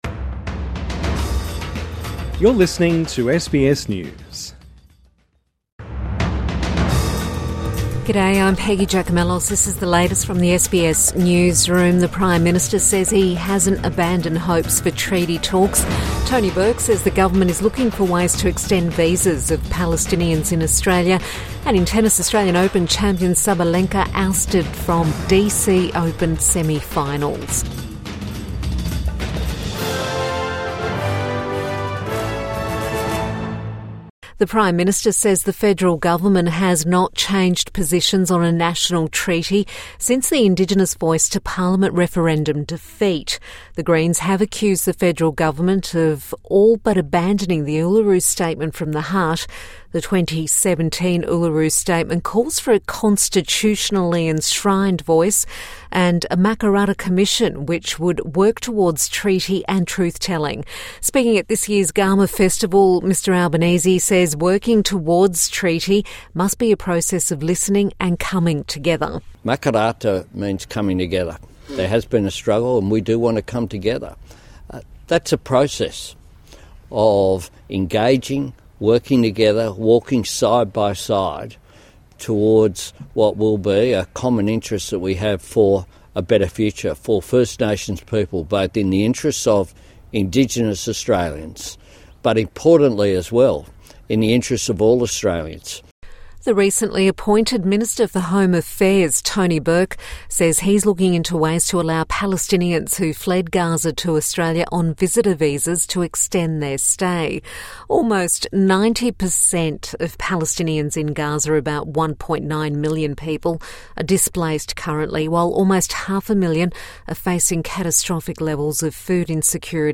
Evening News Bulletin 4 August 2024